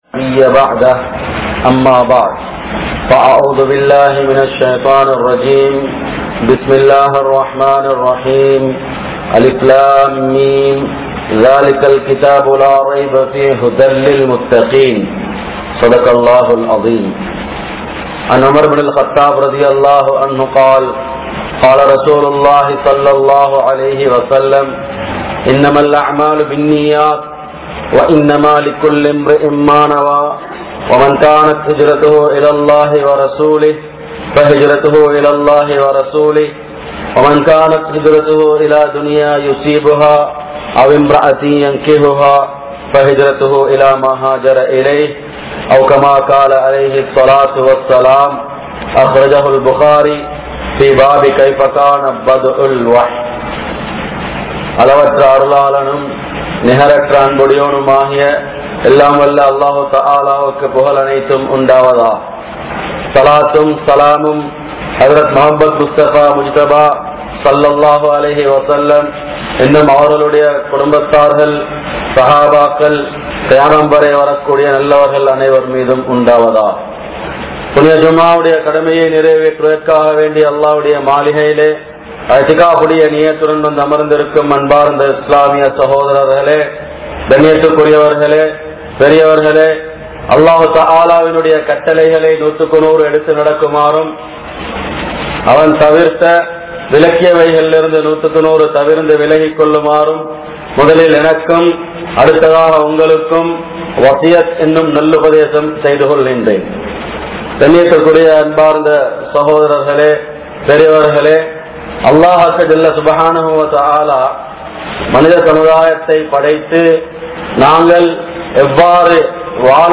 Ibrahim(Alai)Avarhalin Panpuhal (இப்றாஹீம்(அலை)அவர்களின் பண்புகள்) | Audio Bayans | All Ceylon Muslim Youth Community | Addalaichenai
Saliheen Jumua Masjidh